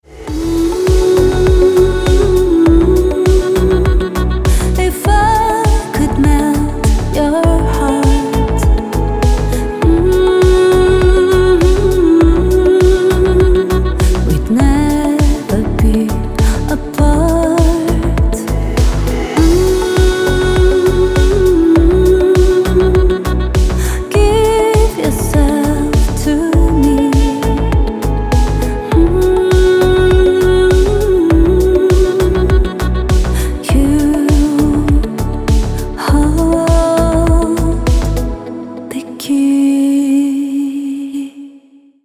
Спокойные рингтоны